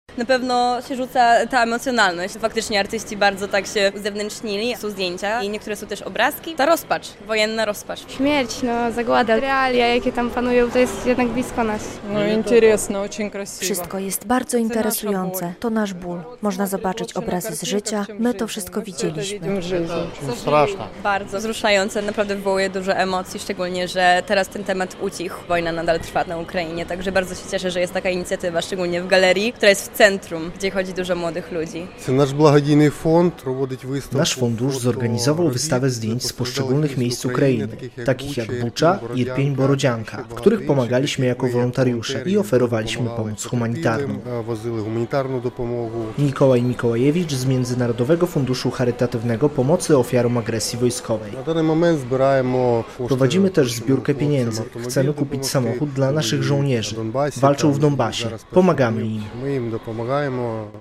Wystawa malarstwa i fotografii ukraińskich artystów w Galerii Jurowiecka - relacja